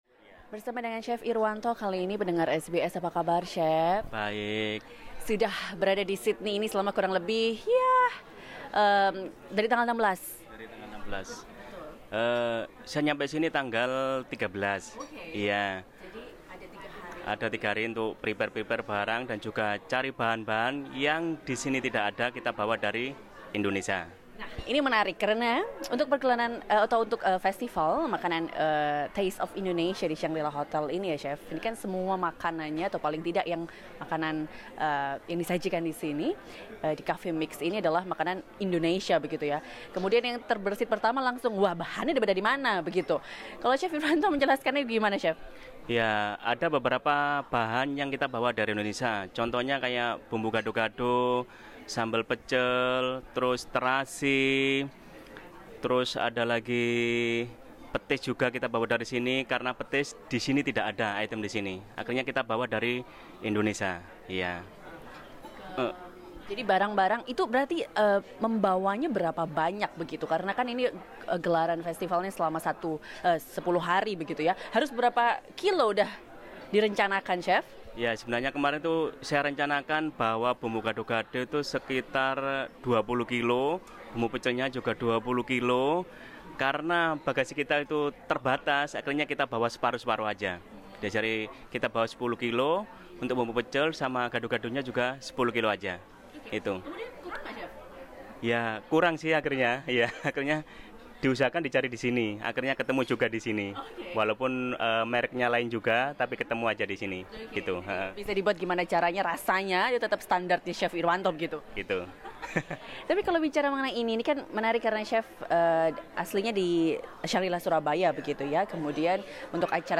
They spoke to SBS Indonesian on this matter.